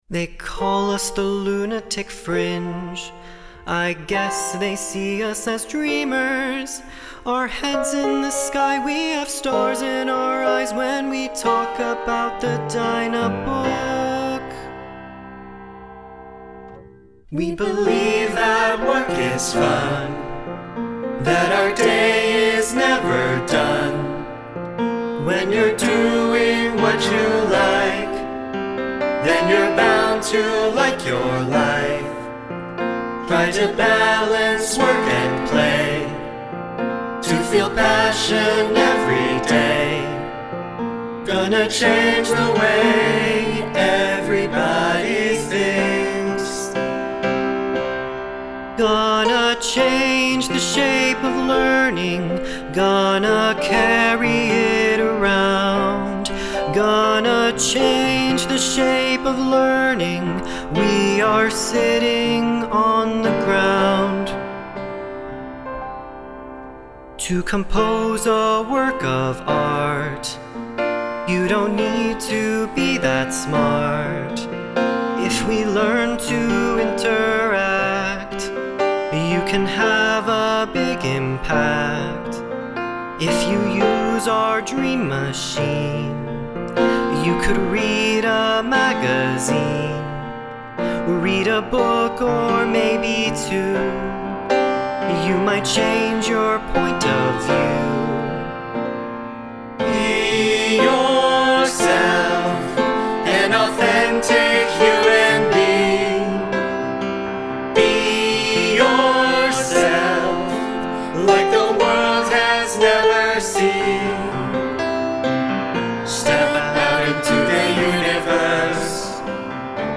(Scene 3a) Shape of Learning – Alan and other scientists of Learning Research Group regale Adele with song about Dynabook and LRG work culture.